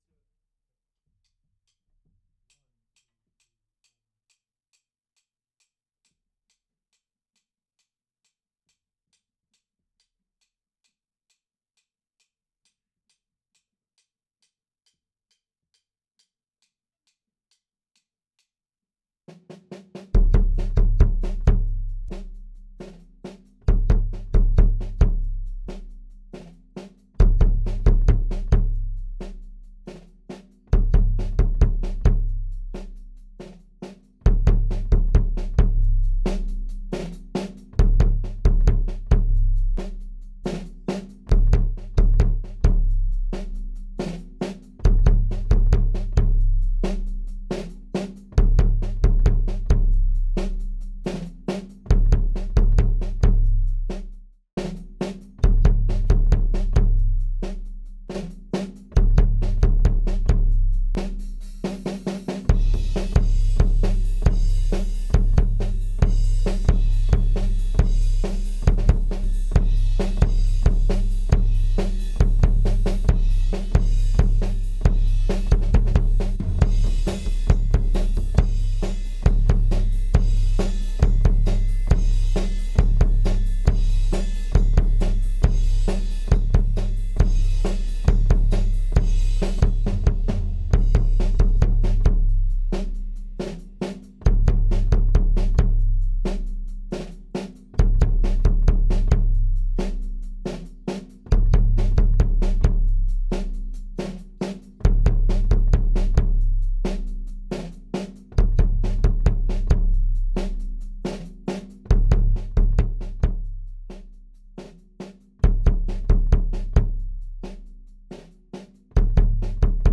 Kick2Compressed.wav